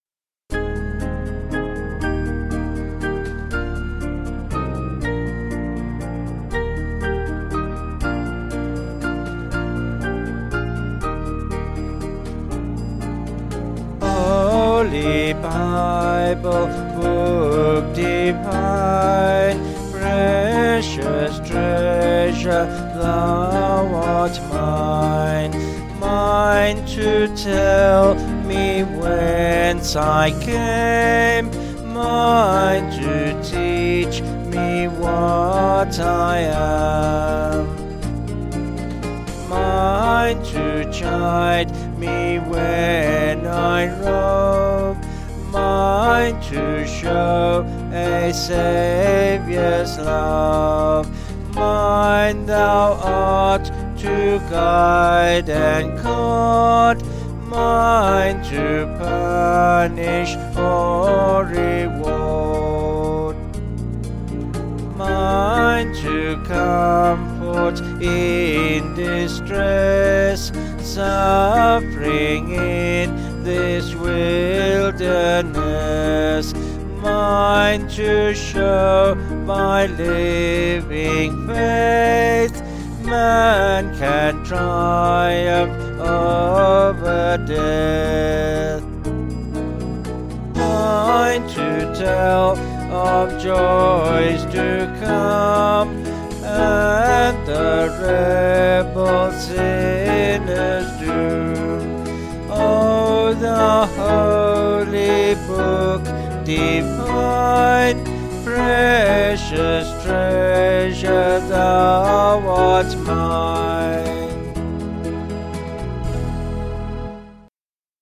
Vocals and Band   264.9kb Sung Lyrics